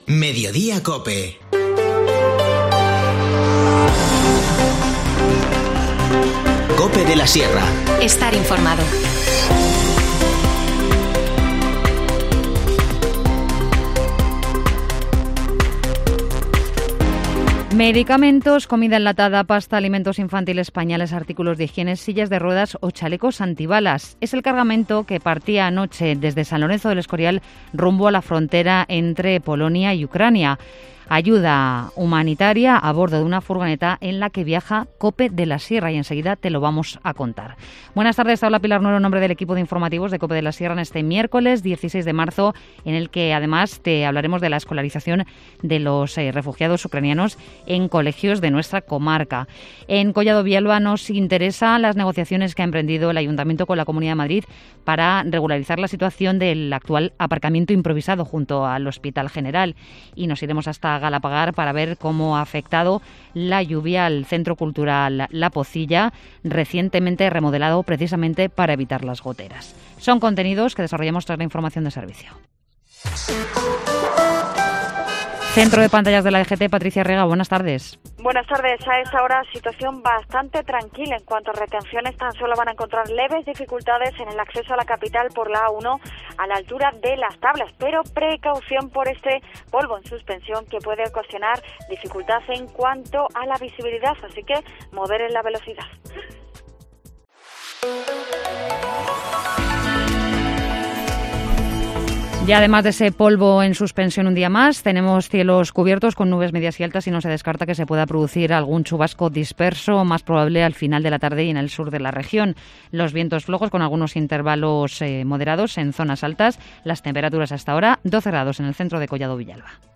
Informativo Mediodía 16 marzo